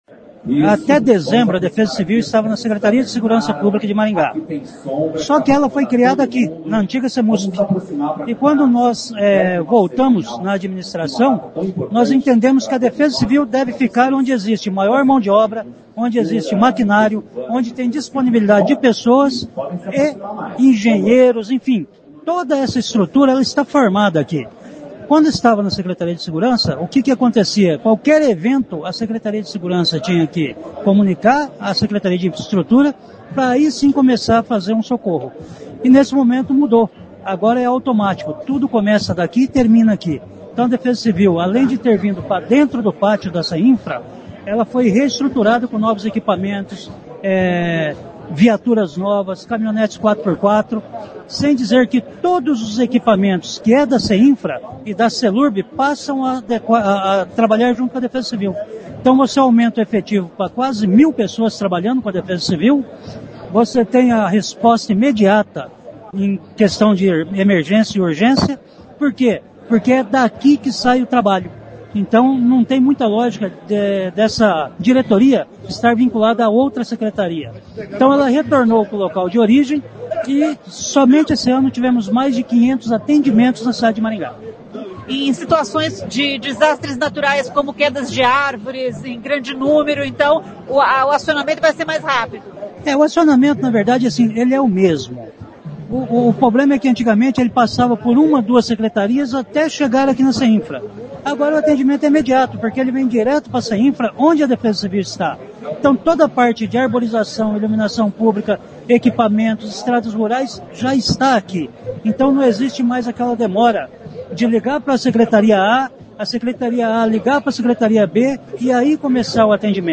O secretário de Infraestrutura e Limpeza Urbana e comandante da Defesa Civil, Vagner Mussio, explica que a mudança dá mais agilidade à resposta em casos de acidentes, ou desastres naturais. O número de veículos aumentou de dois para sete.